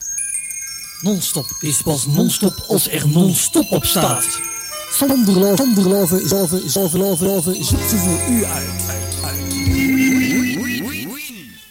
Here are some jingles.